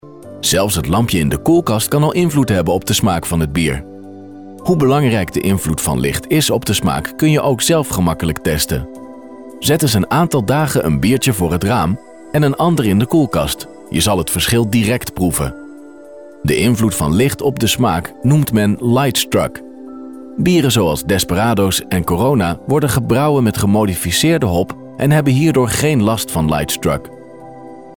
Comercial, Profundo, Amable, Cálida, Empresarial
E-learning